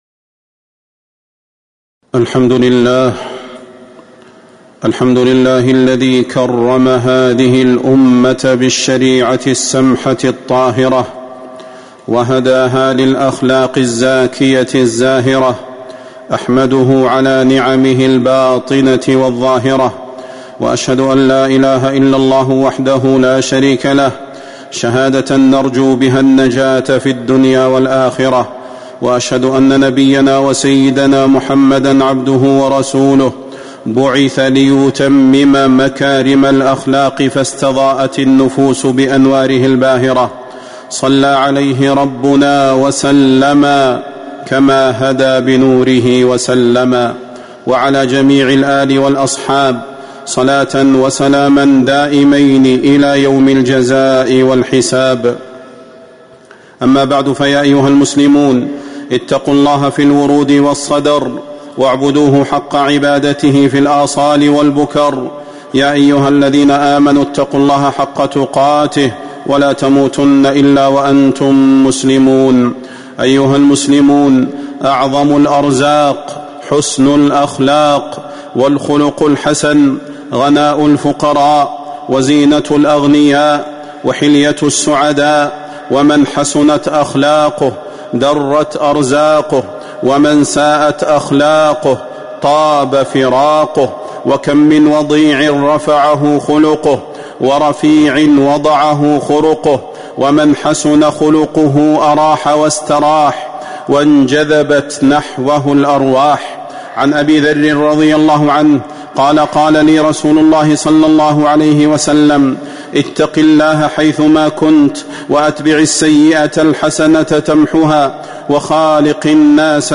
تاريخ النشر ١٦ جمادى الآخرة ١٤٤٥ هـ المكان: المسجد النبوي الشيخ: فضيلة الشيخ د. صلاح بن محمد البدير فضيلة الشيخ د. صلاح بن محمد البدير خير الأرزاق حسن الأخلاق